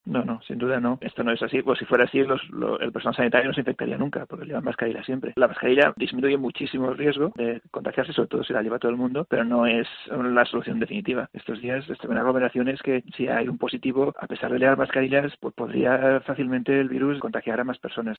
En COPE hemos entrevistado a una sanitaria y a un experto para que nos respondan las preguntas más básicas, y que nos pueden hacer entender el por qué del riesgo de que se formen aglomeraciones.
médico y epidemiólogo